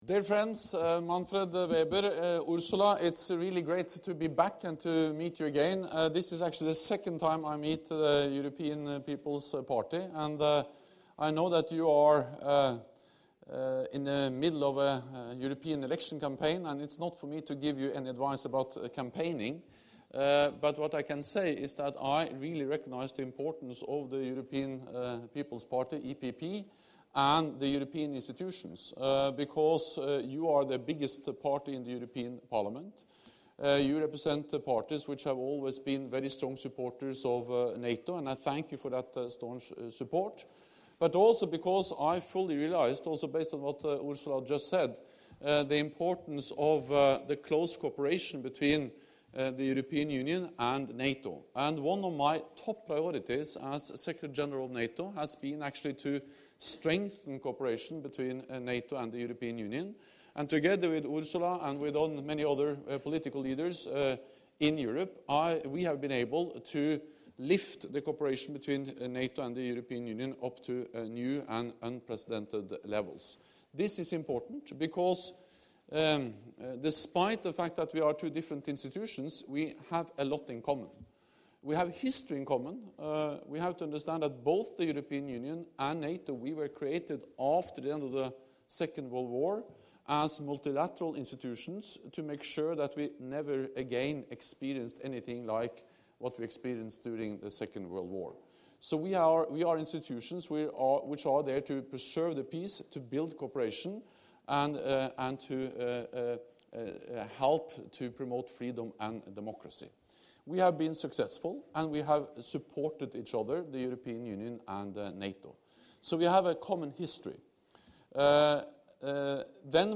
Speech
by NATO Secretary General Jens Stoltenberg at the European People's Party